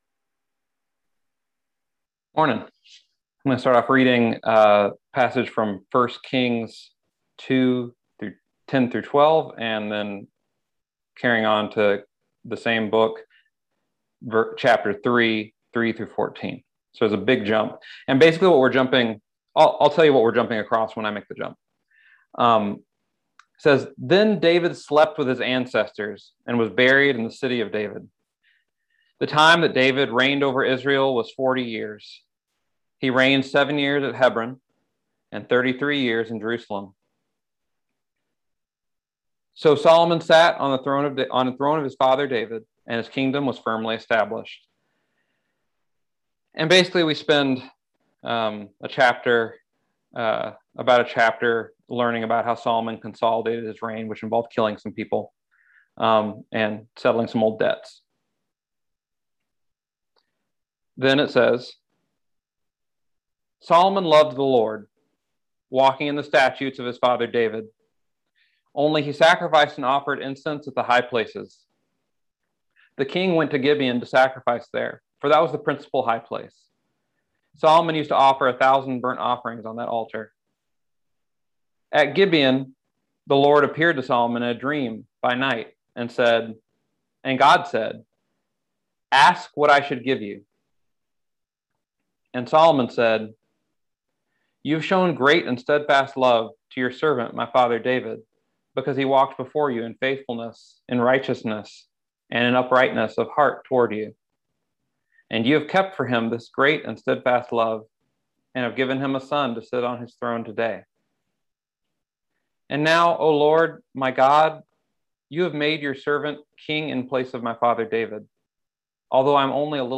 Listen to the most recent message from Sunday worship at Berkeley Friends Church, “Choosing Wisdom.”